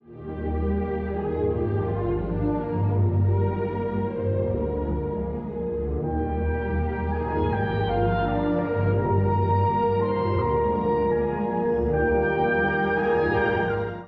一方で第二主題は、穏やかで流れるような旋律。
再現部でも緊張感は持続したまま突き進みます。
しかしクライマックスの後、音楽はふっと力を失っていきます。